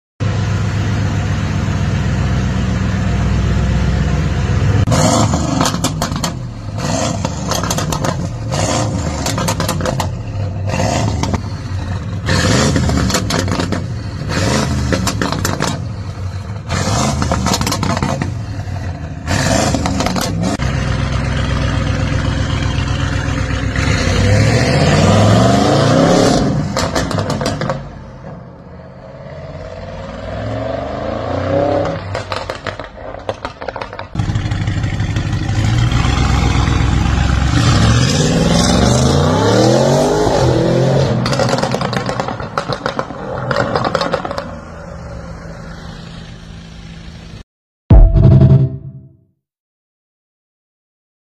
STAGE 2 AUDI RS6 C8 Sound Effects Free Download
STAGE 2 AUDI RS6 C8 DOWNPIPES & PRIMARY RESONATORS REMOVED.